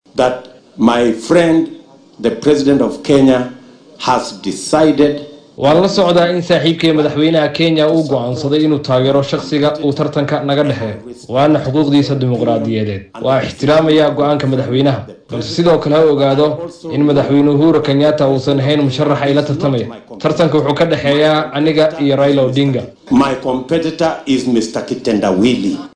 William Ruto oo hadalkan ka jeediyay xilli uu shalay ololihiisa uu ku doonaya xilka madaxtinimo ee dalka geeyay ismaamulka Kitui ayaa dhanka kale ka codsaday hoggaamiyaha xisbiga ODM inuu joojiyo inuu gabaad ka dhigto madaxweynaha dalka Uhuru Kenyatta.